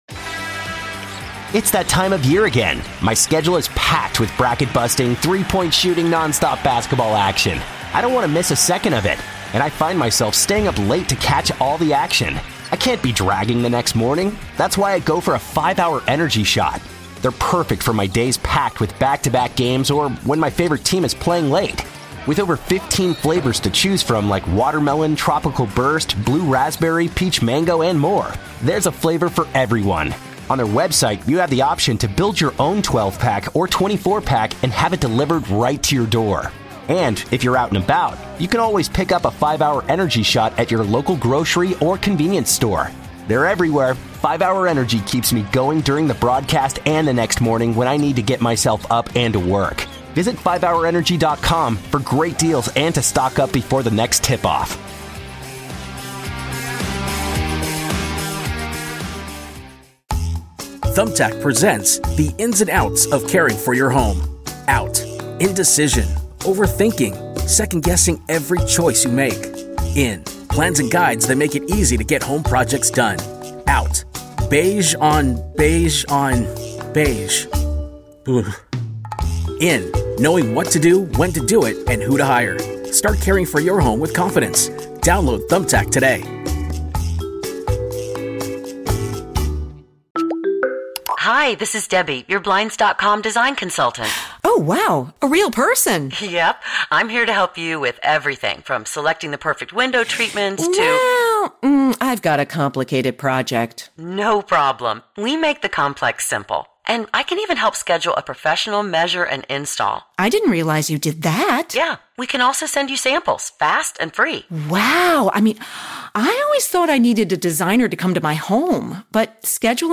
His passion, knowledge, and historical perspective has created a legion of fans and made his show the premier destination for DC sports talk.